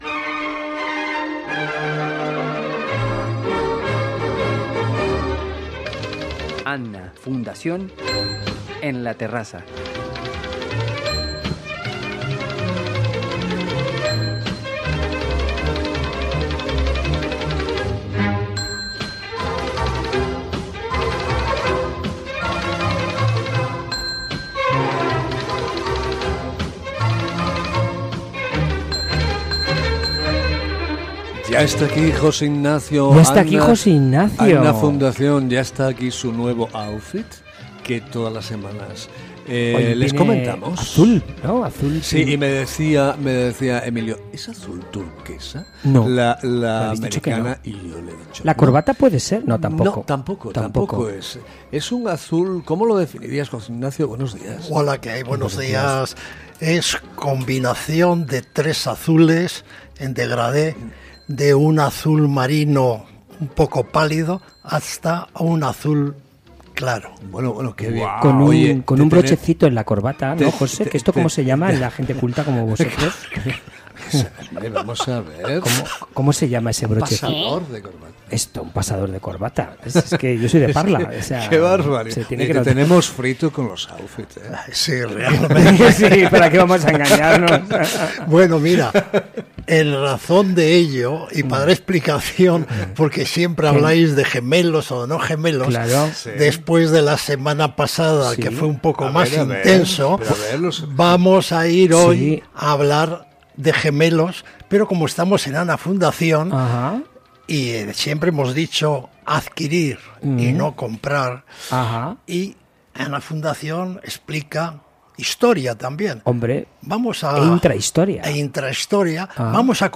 Muy simpática la tertulia. Desenfadada y con buen humor.